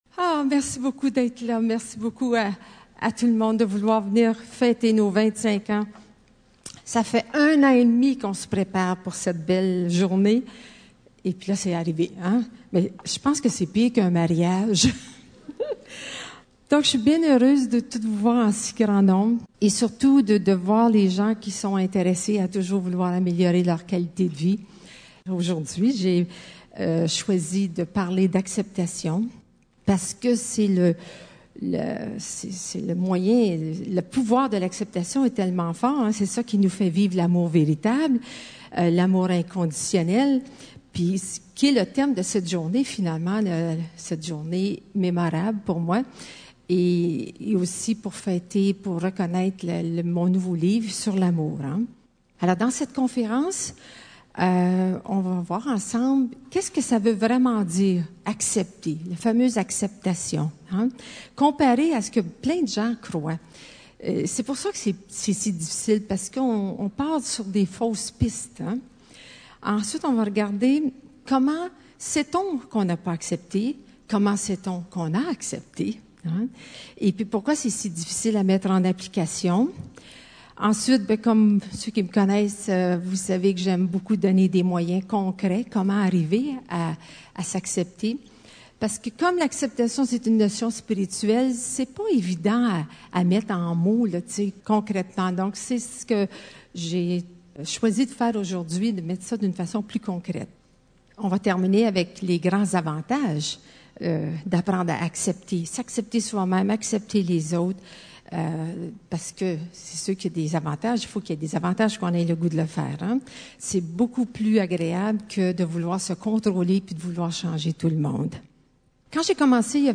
Voici les 6 pistes audio de la conférence "La puissance de l'acceptation".